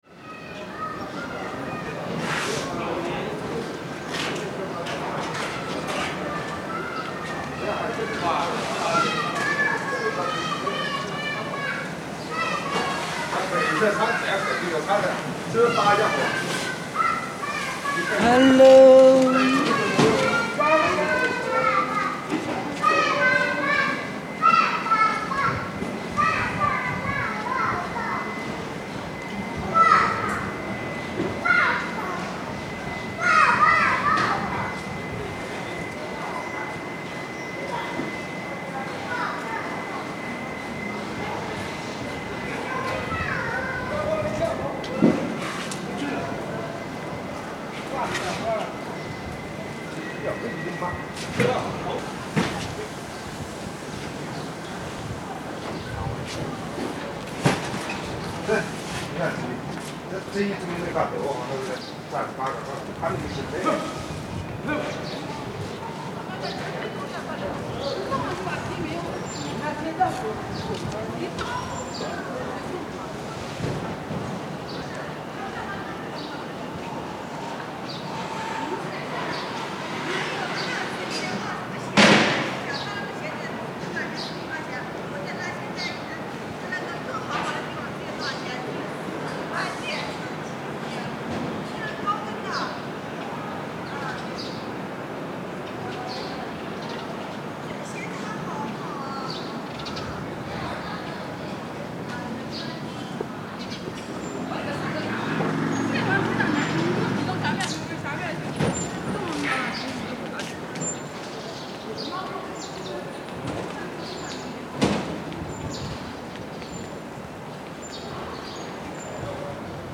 This recording was made one late Saturday afternoon and I was taken by the serenity of the whole scenery: children playing, people singing while passing on their bicycles, birds chirping.